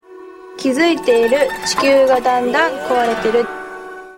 Di sini kita bisa mendengar senryu-senryu yang dibacakan.